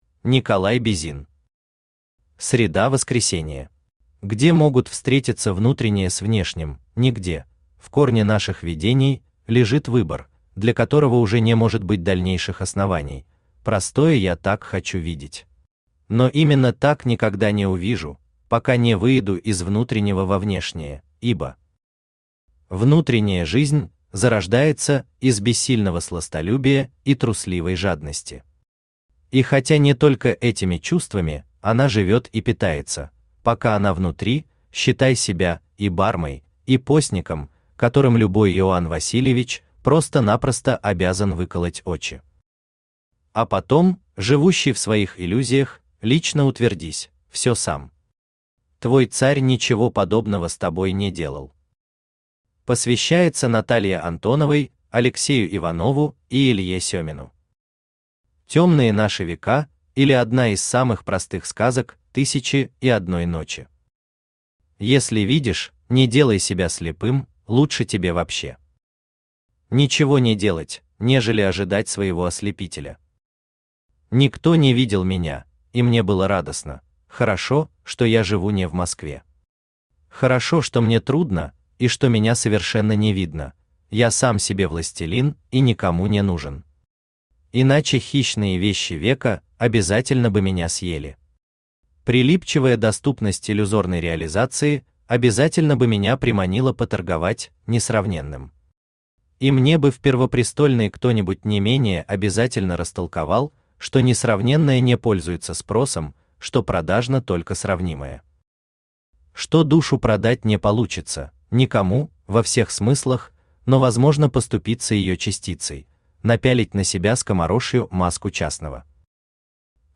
Аудиокнига Среда Воскресения | Библиотека аудиокниг
Aудиокнига Среда Воскресения Автор Николай Бизин Читает аудиокнигу Авточтец ЛитРес.